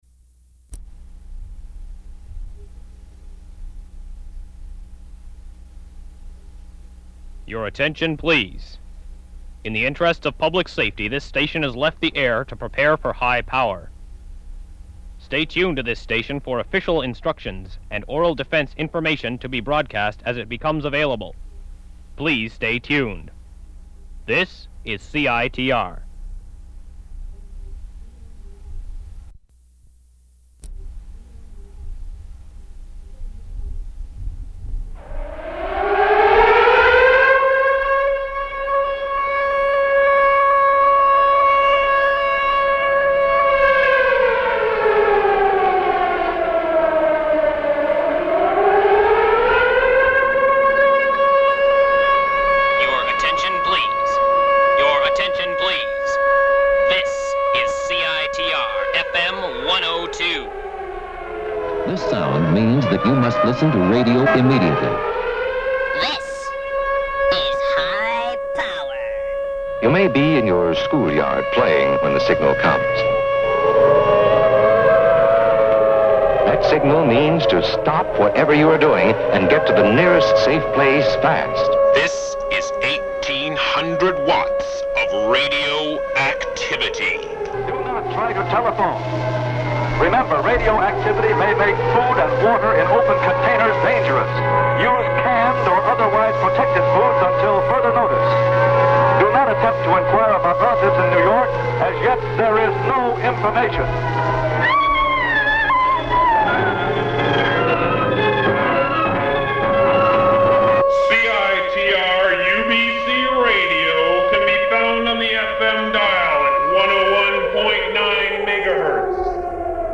Recording of CiTR's announcement of transition to high-power FM 101.9 MHz, followed by interviews with various CiTR staff.